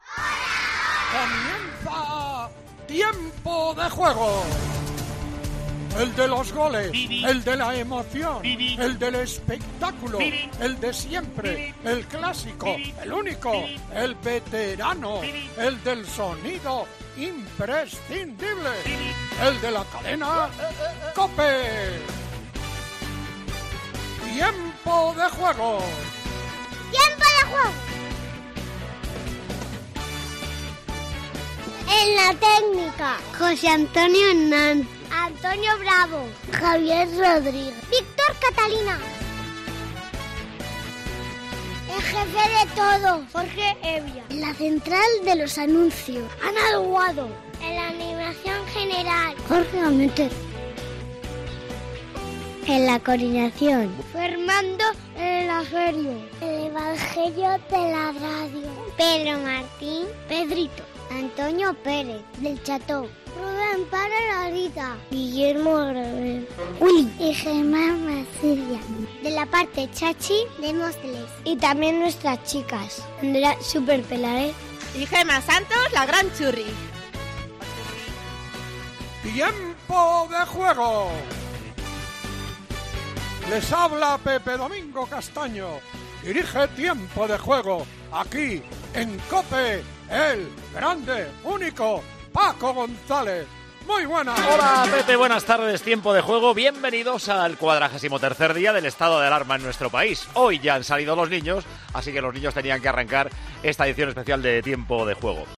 Especial careta de ' Tiempo de Juego' hecha por los más pequeños